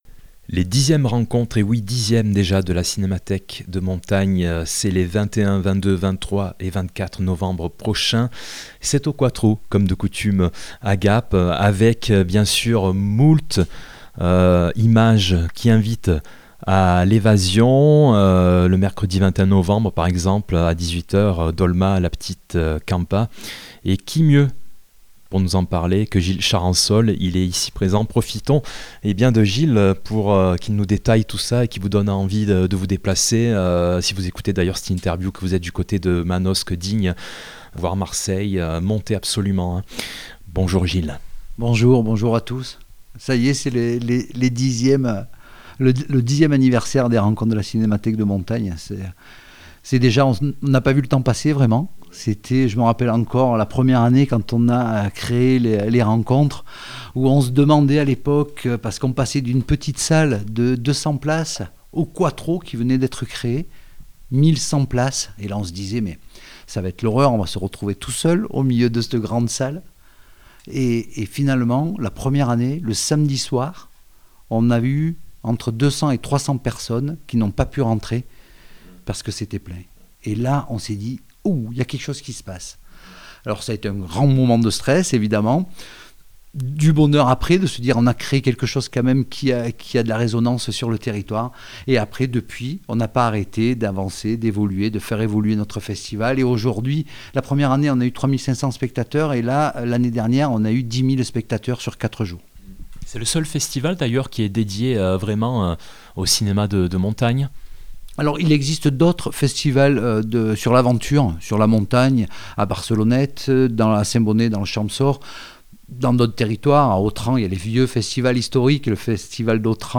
émission 10 ans rencontres cim.mp3 (36.11 Mo)